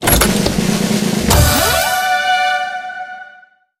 02_treasure_open_01.wav